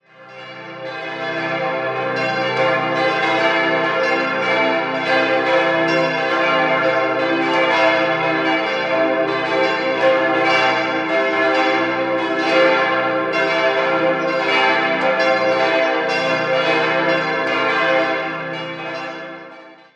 Vom Glaskünstler Erich Schickling (*1924) wurden die vier großen Fenster entworfen. 5-stimmiges Geläut: c'-e'-g'-a'-c'' Alle Glocken wurden 1964 von Rudolf Perner in Passau gegossen.